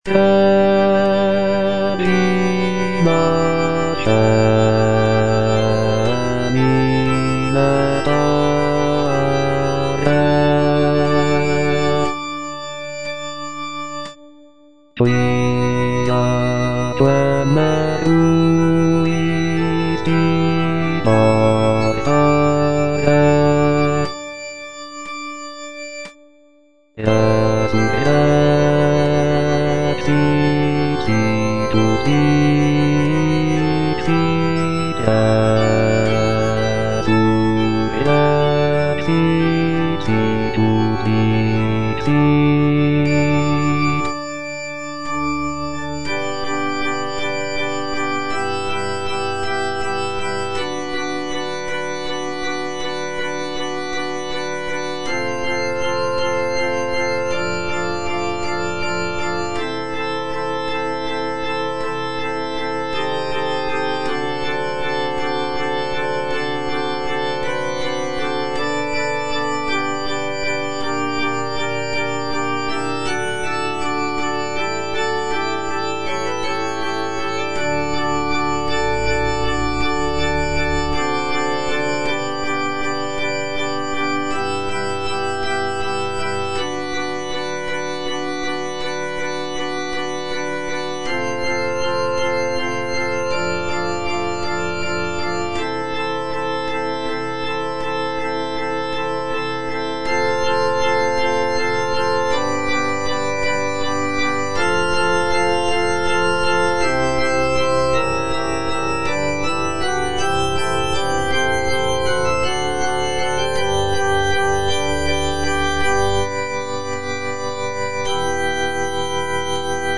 internal choir, bass) (Voice with metronome